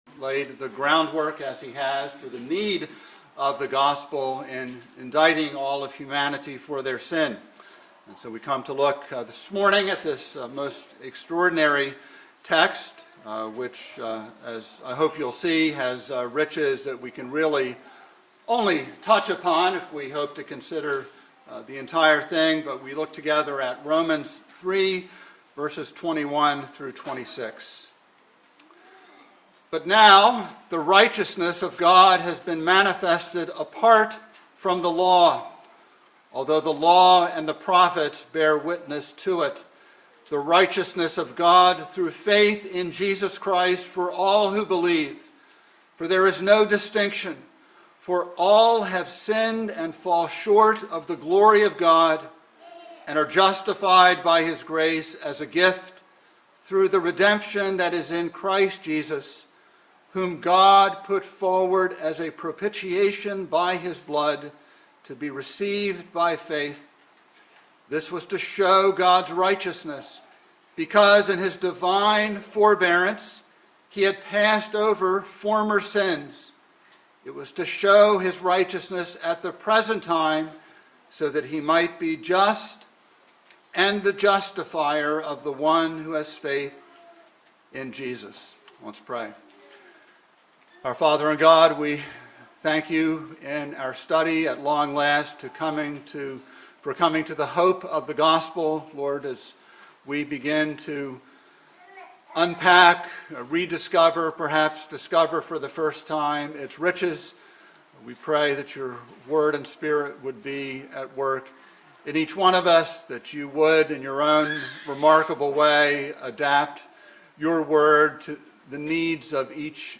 by Trinity Presbyterian Church | Feb 7, 2022 | Sermon